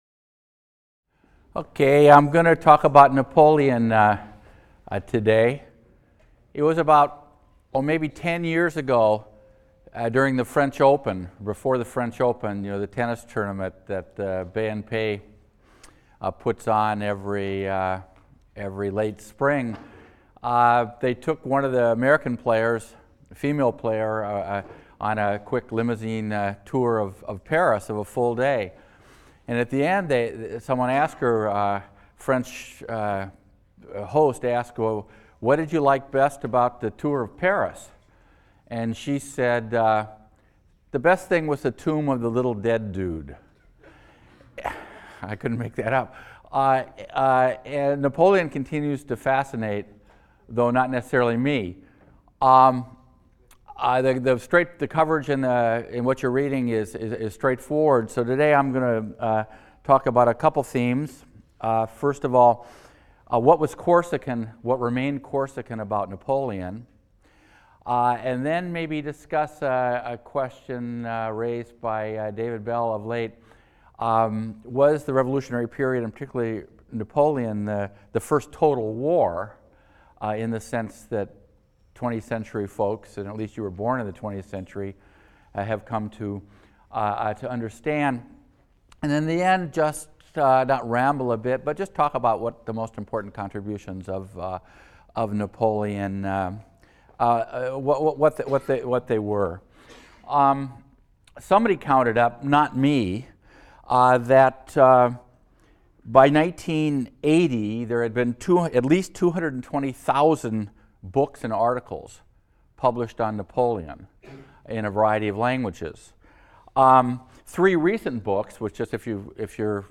HIST 202 - Lecture 7 - Napoleon | Open Yale Courses